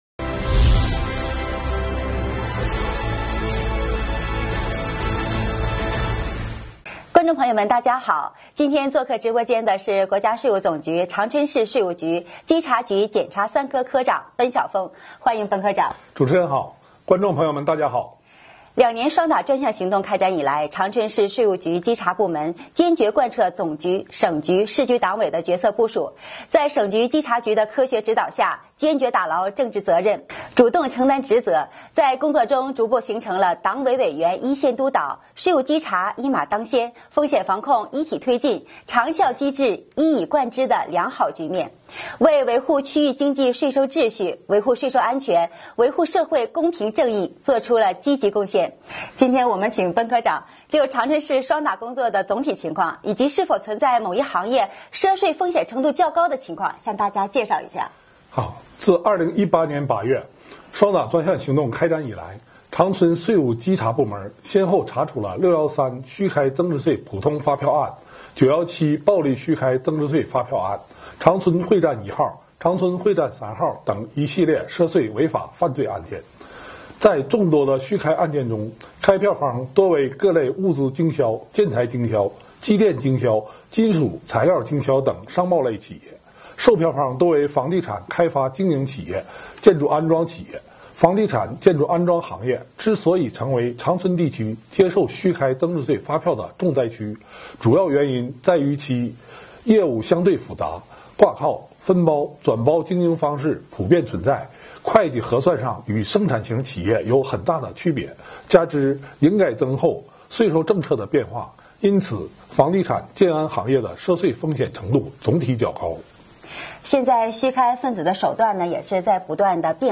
2021年第29期直播回放：长春市税务局“以案说法”系列之——长春市税务局稽查局两年“双打”专项行动